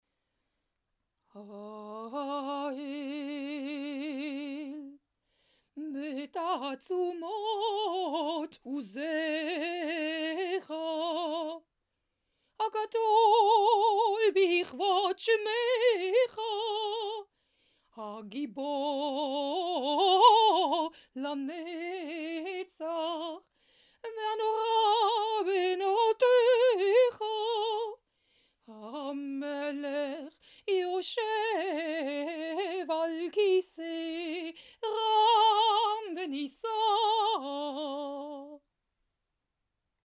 This is chanted with a very specific tune (see below).